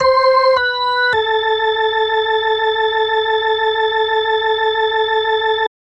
Organ 03.wav